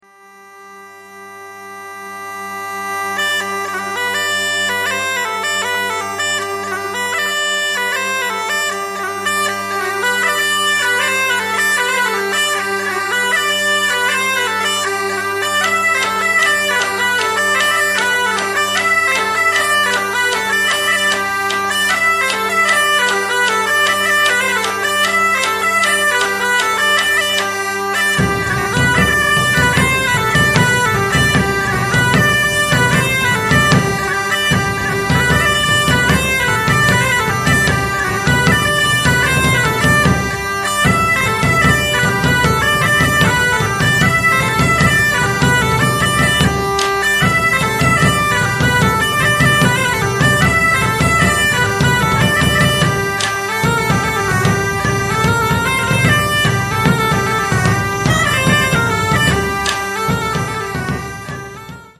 Il Gruppo di musica folk & celtica